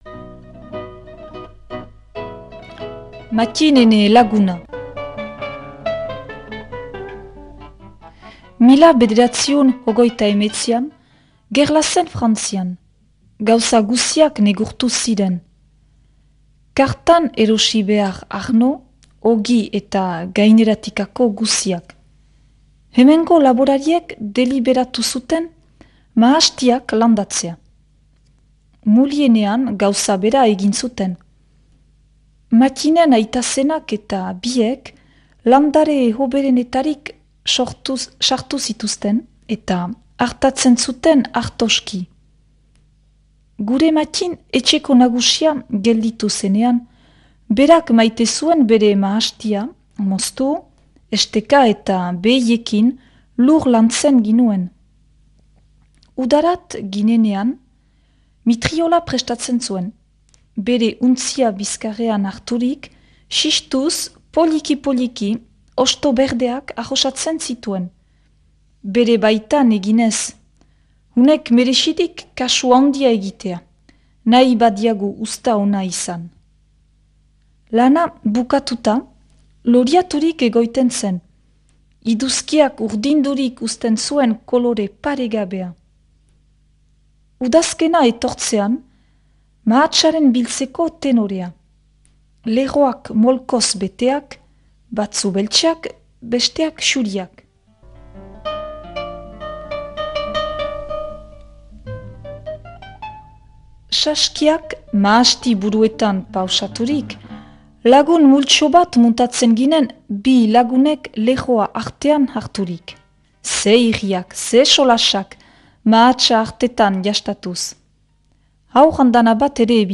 proposatu irakurketa da.